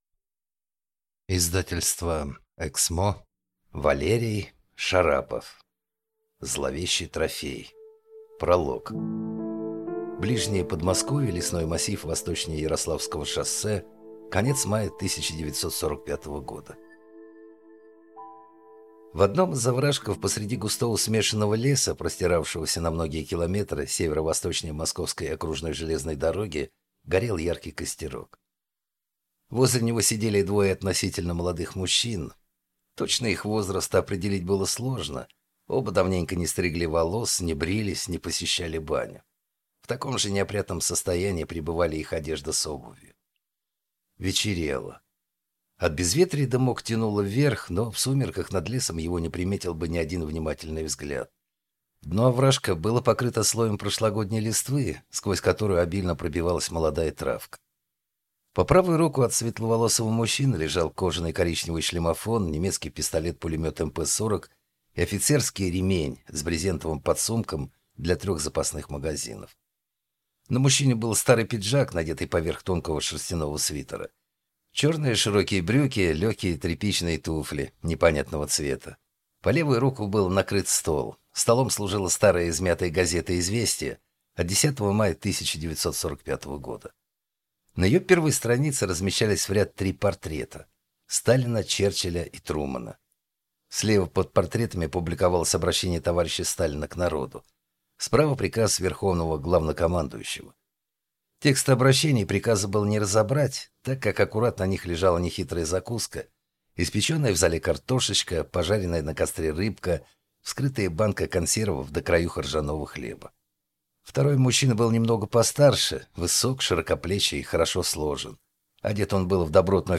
Аудиокнига Зловещий трофей | Библиотека аудиокниг